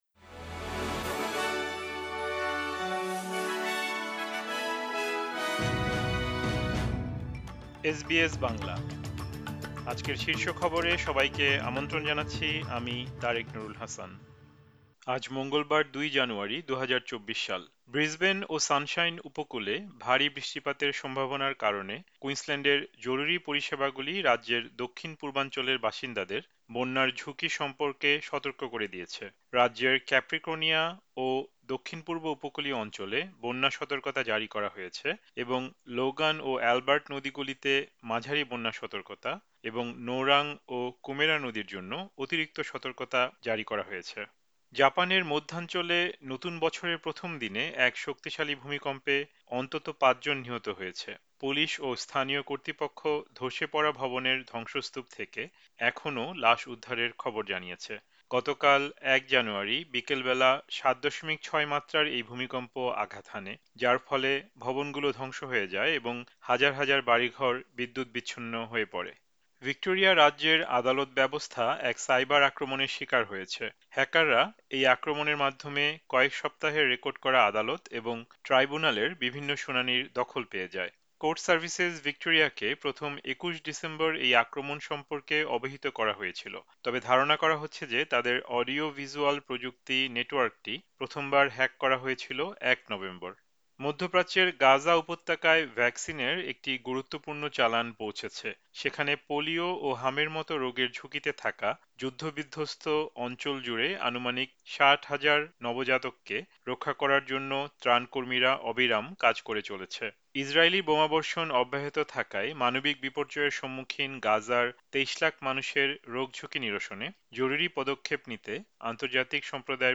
এসবিএস বাংলা শীর্ষ খবর: ২ জানুয়ারি, ২০২৪